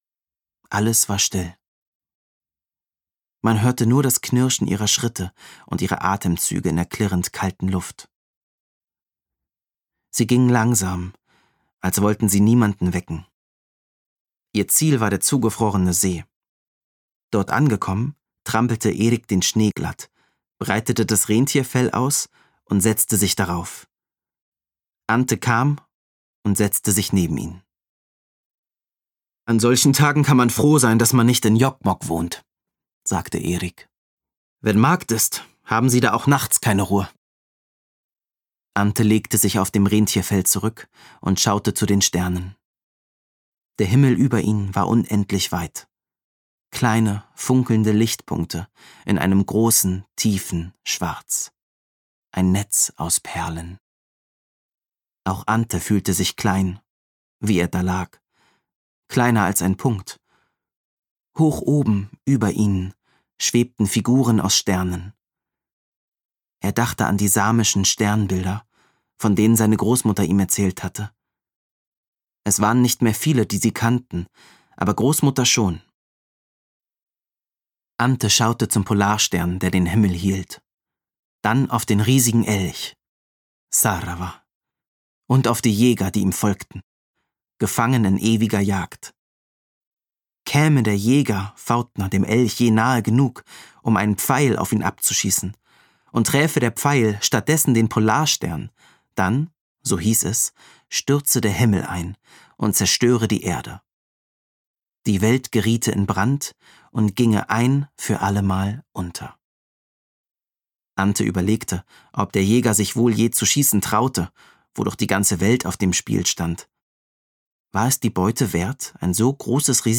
Jannik Schümann erzählt mit viel Einfühlungsverögen von Ántes Weg zu sich selbst. Mit jugendlicher Stimme begleitet er den liebenswerten Außenseiter durch die kühle Umgebung Schwedens.
Himmelsfeuer Gelesen von: Jannik Schümann